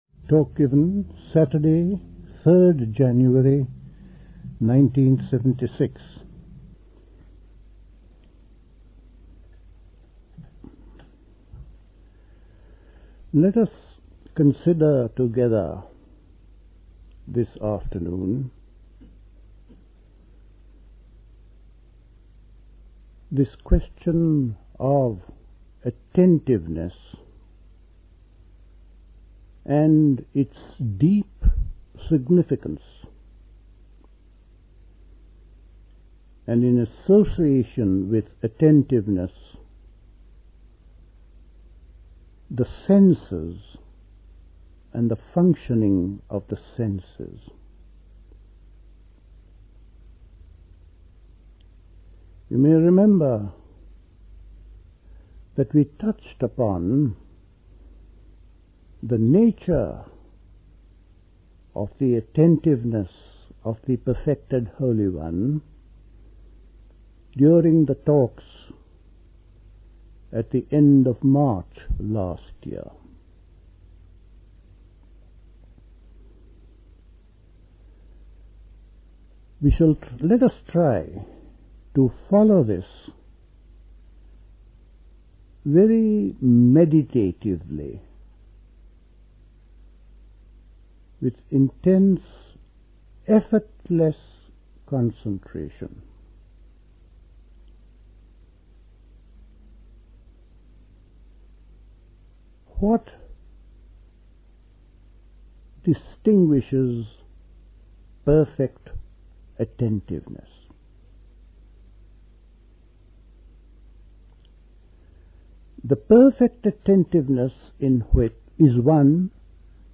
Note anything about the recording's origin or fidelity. Dilkusha, Forest Hill, London